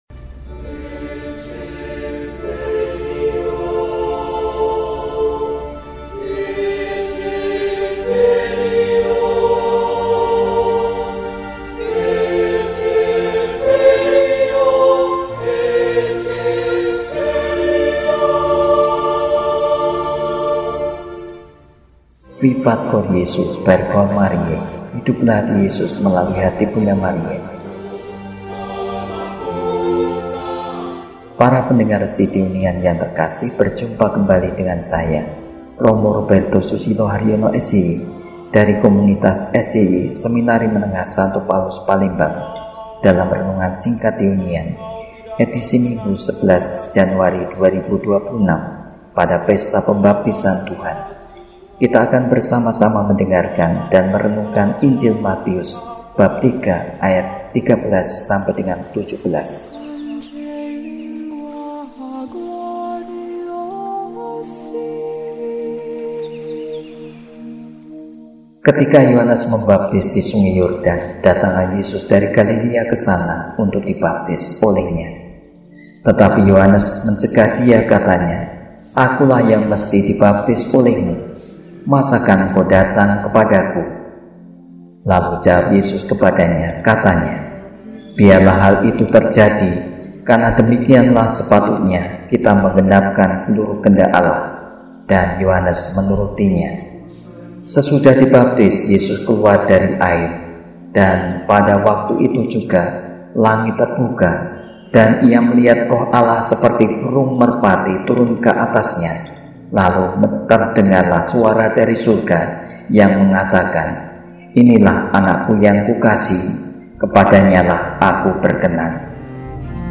Minggu, 11 Januari 2026 – Pesta Pembaptisan Tuhan – RESI (Renungan Singkat) DEHONIAN